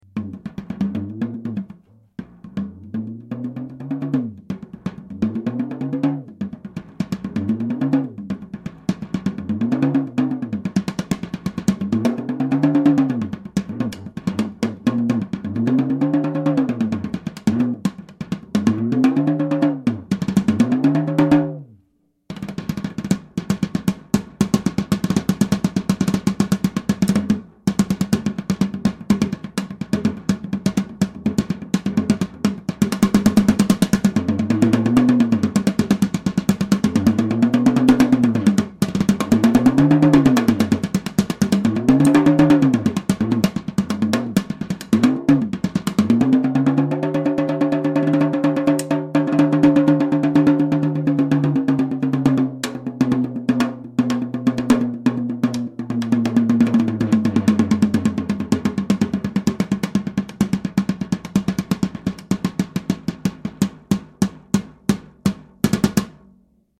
instantly from low end to high end or anywhere in between using the pedal.
Attractive features of this drum are it’s deep bass and wide range of  pitch.
This looseness combined with the bass response of this drum body size, emits a deep “thwack” wet sound bass note when struck.   With the footpedal lightly depressed; the wet sound dissapears and a deep resonance appears. This resonance stays with the drum up to the top end of it’s upper range.